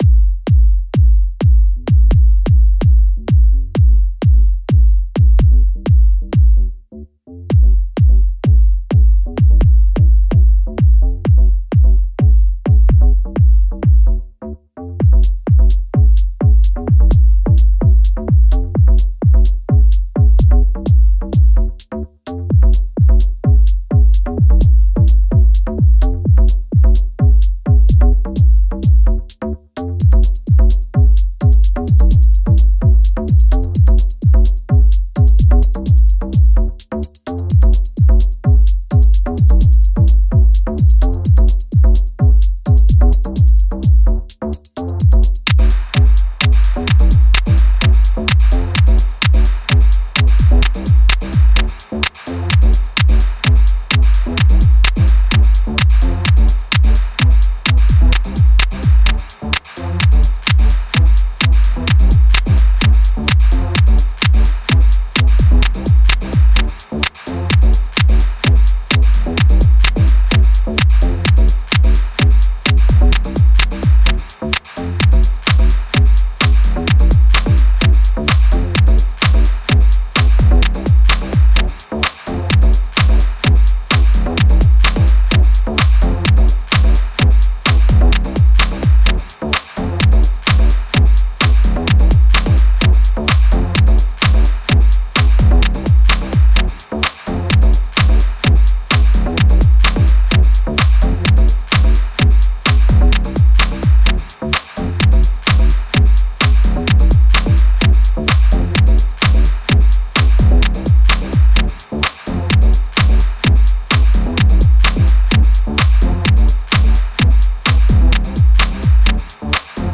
Blick auf das Atomium vom Square de l'Atomium in Bruxelles